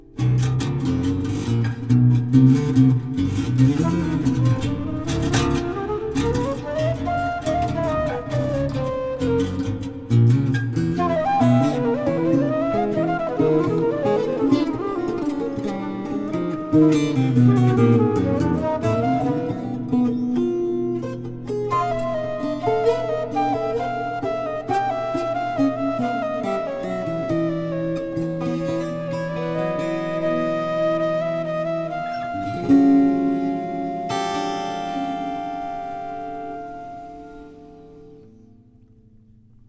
Tenor and Soprano saxophones, Alto flute